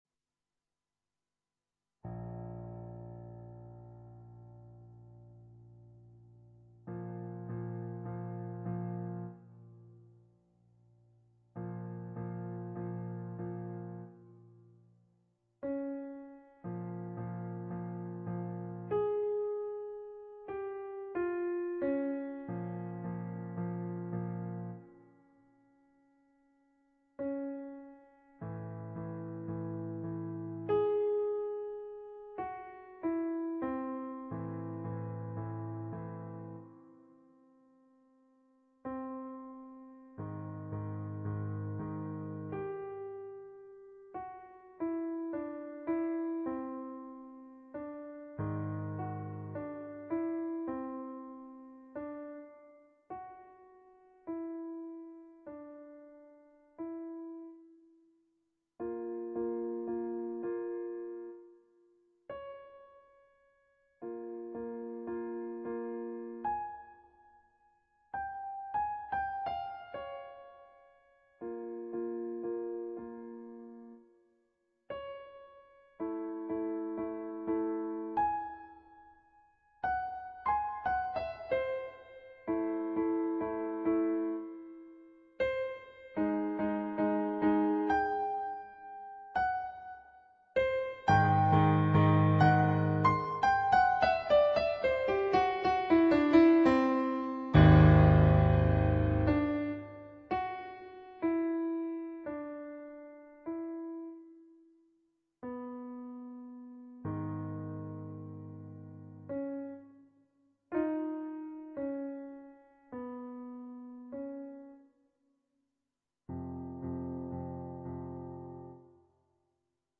Lento (2'17")
on Yamaha digital pianos.